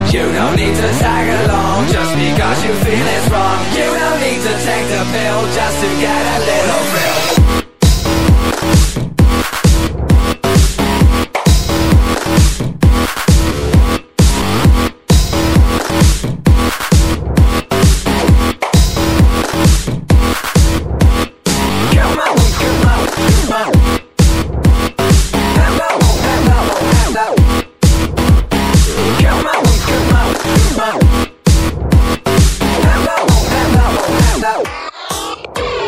Просто жестяная нарезочка
Таги: DJ, mp3, РєР»СѓР±РЅР°СЏ, РЅР°СЂРµР·РєР°